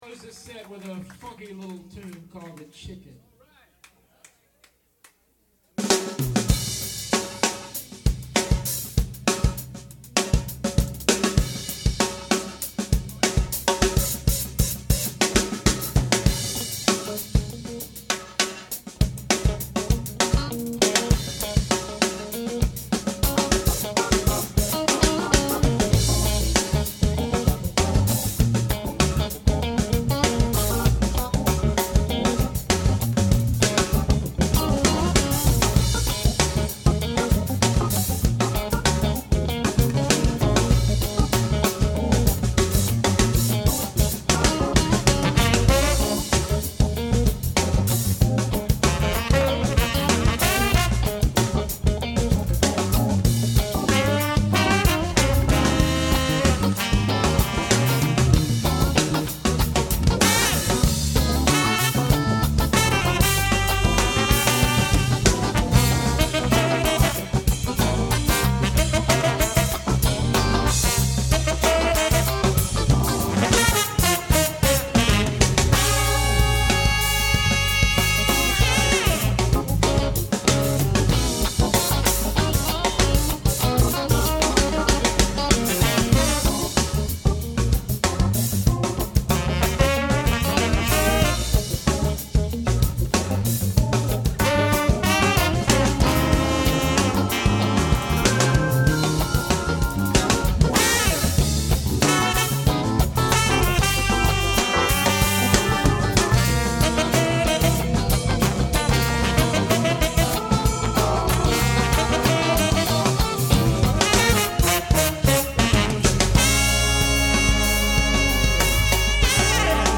Recorded on Tascam DA-78’s.